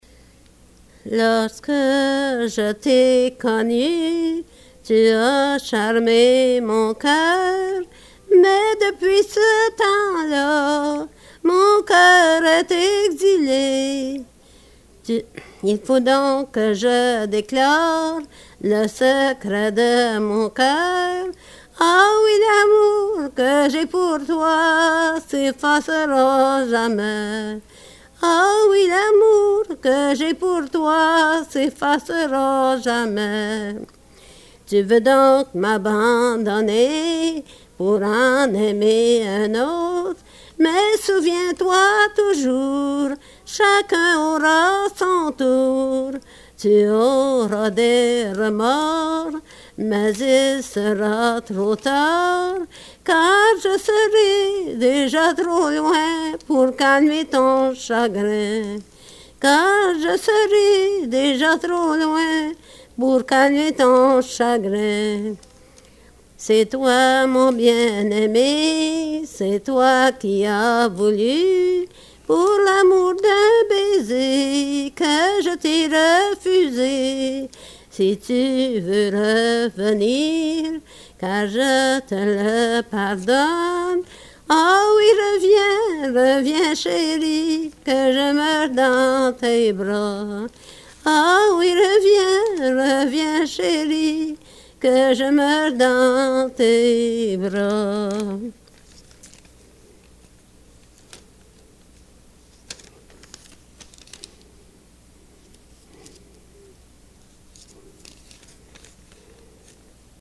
Excerpt from interview
“Lorsque je t’ai connu“, (“When I met you “) is a sentimental romance which relates a story of abandonment, forgiveness and reconciliation between two sweethearts.
sound cassette (analog)